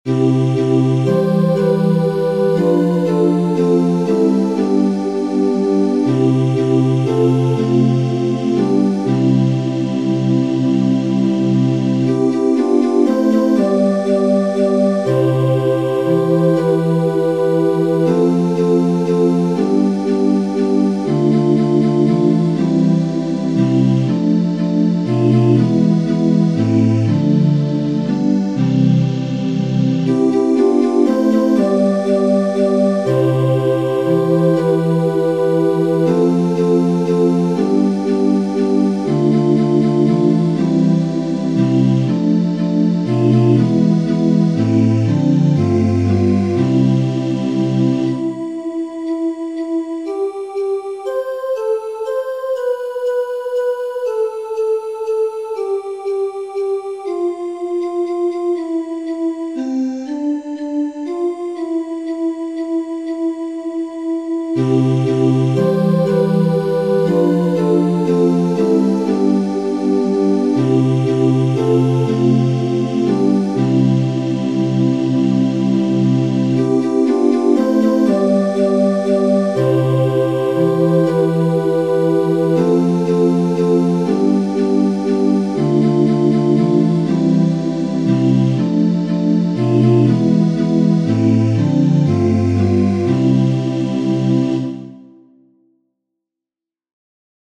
Chant thématique pour le Sixième Dimanche de Pâques -A